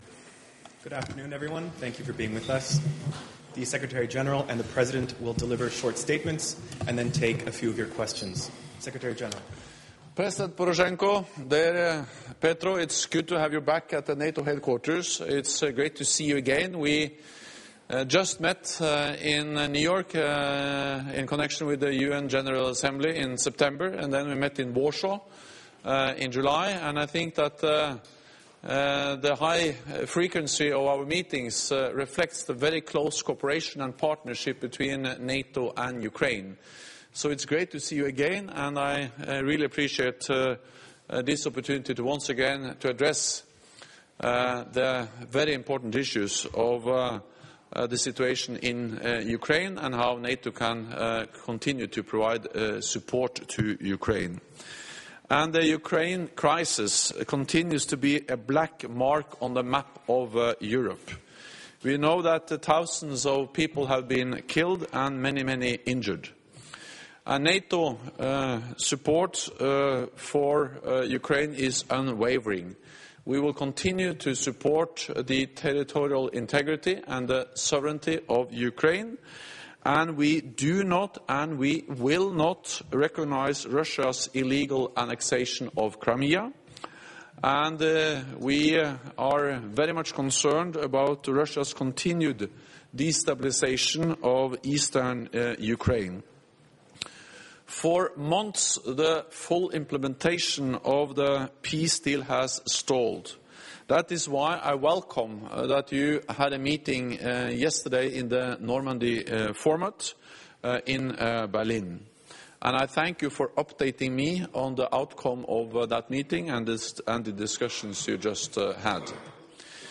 Joint press point with NATO Secretary General Jens Stoltenberg and the President of Ukraine, Petro Poroshenko